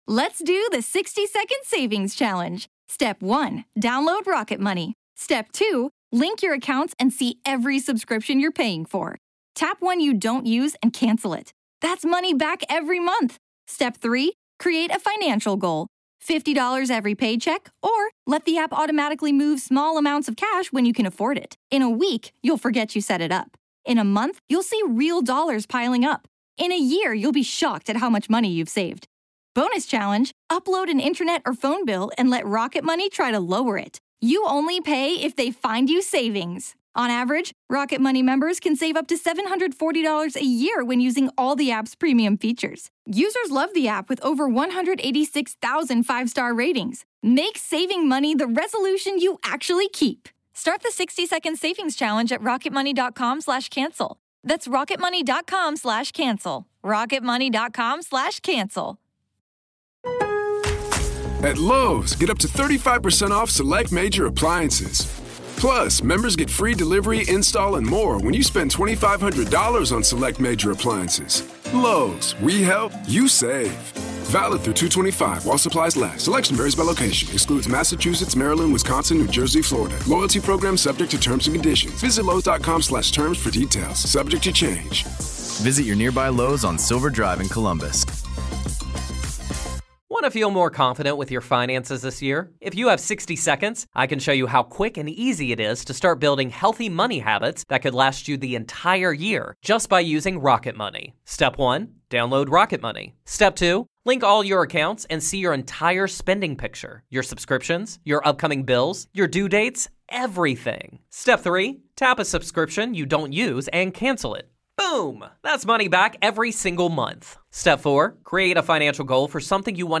The voice said, "Get out," and the team did.
This is an excerpt from a recent episode of one of our other podcasts.